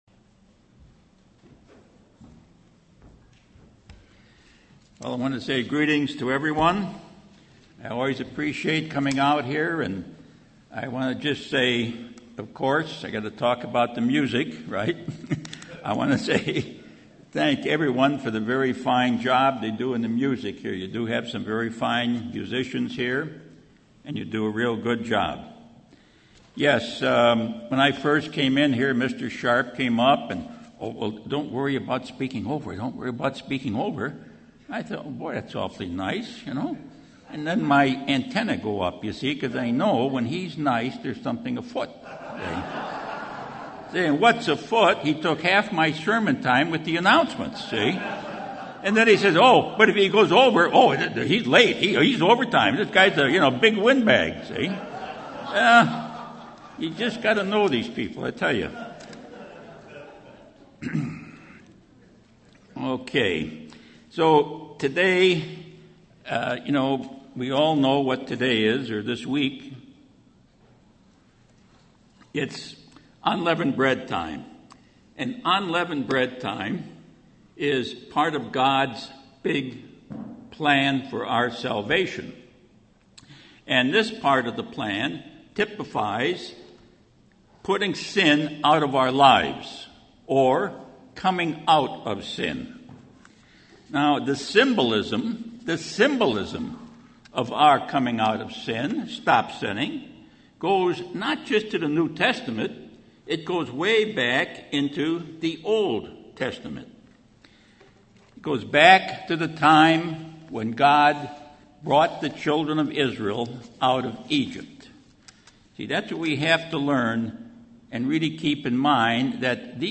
Looking at the plagues of Egypt, what personal lessons are there? What can we leave behind in our personal Egypt? This message was given on the First Day of Unleavened Bread.
Given in Redlands, CA
UCG Sermon Studying the bible?